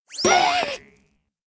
avatar_emotion_surprise.ogg